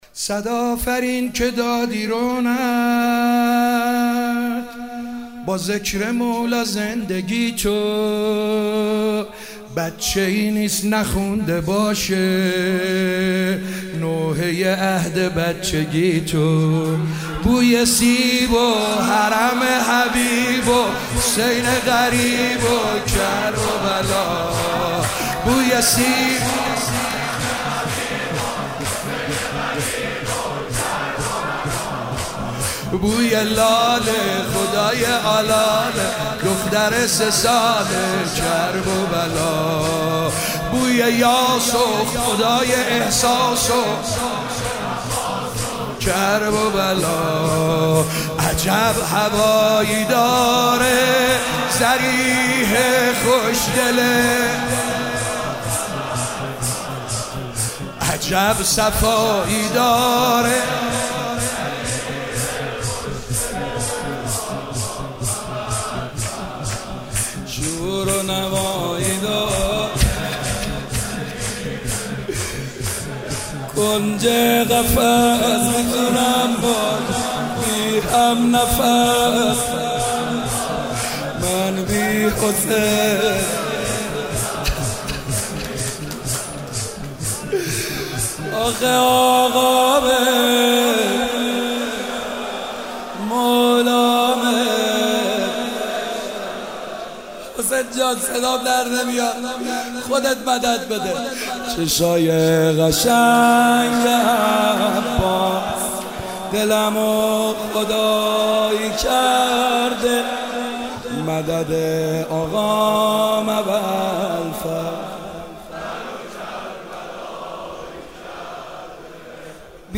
کربلایی محمدحسین حدادیان
شب سوم محرم97 - شور - صد آفرین که دادی رونق